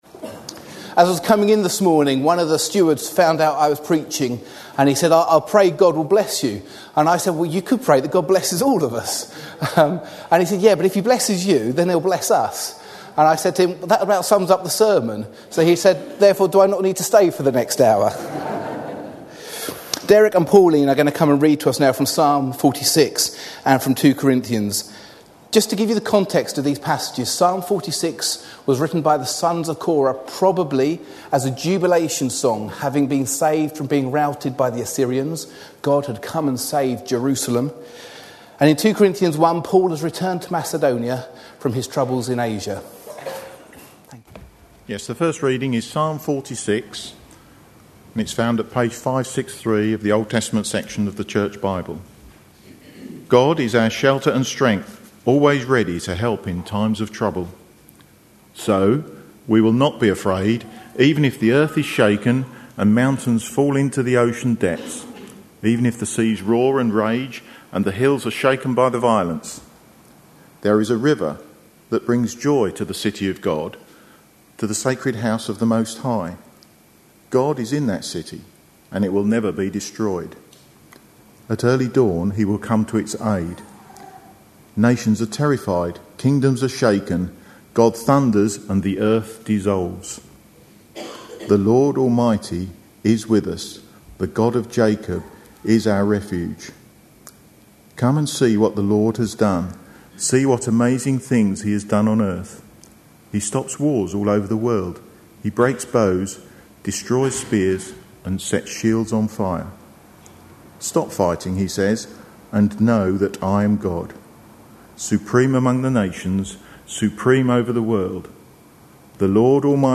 A sermon preached on 22nd May, 2011, as part of our God At Work In Our Lives. series.